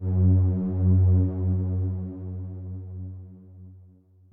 b_basspad_v127l1o3fp.ogg